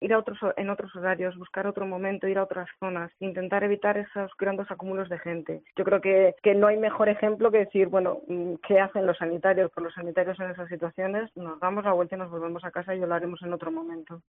En COPE hemos entrevistado a una sanitaria y a un experto para que nos respondan las preguntas más básicas, y que nos pueden hacer entender el por qué del riesgo de que se formen aglomeraciones.
médico medicina preventiva